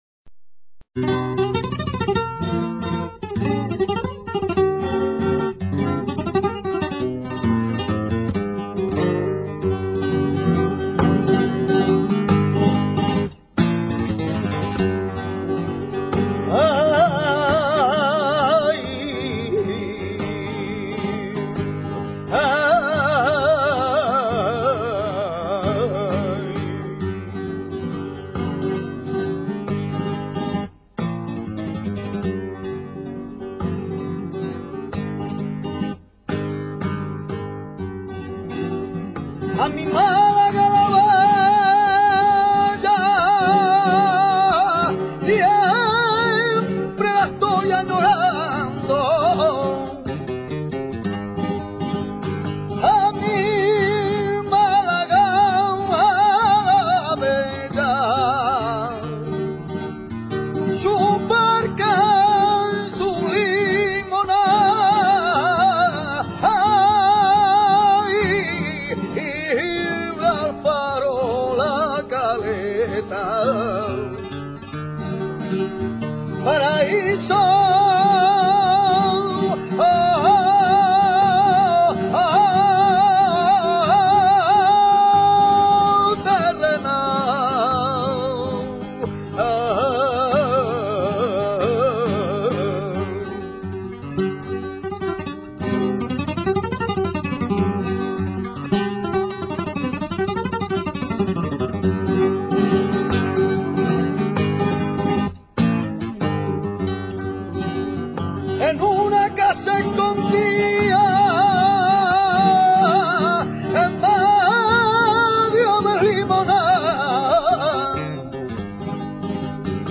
Se le considera el prototipo del fandango campesino. Las letras son sencillas y alegres y, musicalmente presentan un ritmo trepidante y mon�tono que denota su primitivismo. El acompa�amiento no se hace exclusivamente con guitarra, suelen intervenir adem�s violines, panderetas y casta�uelas, y antiguamente vihuelas y bandurrias, a veces sustituidos por instrumentos r�sticos o caseros como almireces, canutos de ca�a, cacharros y cucharas.
Escuchar unos Verdiales
verdiales.mp3